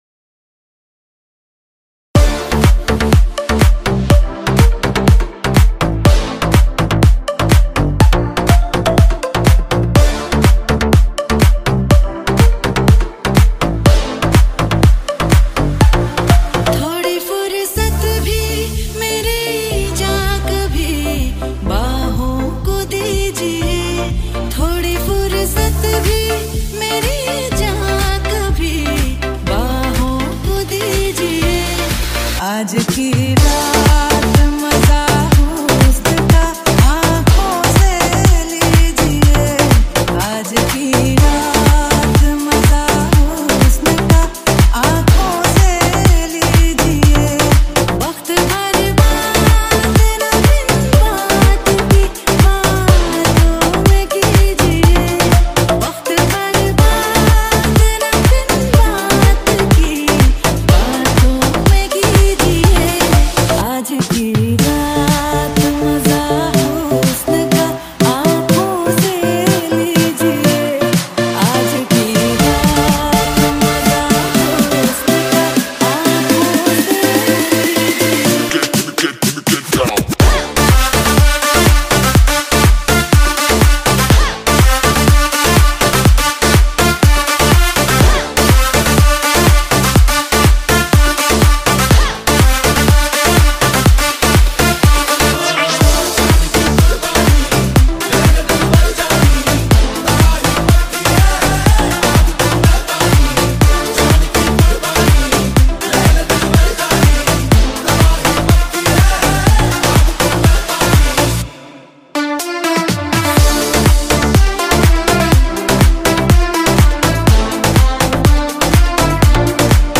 slowed and reverb song